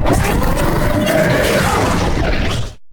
taunt1.ogg